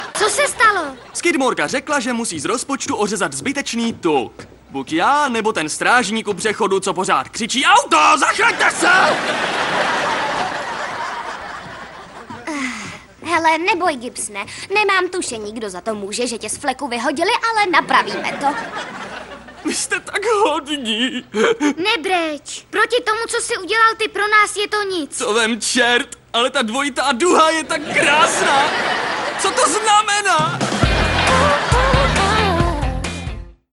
VOICE OVER / KOMENTÁŘ / DABING / ZPĚV
3. DABING-Farma-R.A.K.(Disney Channel).mp3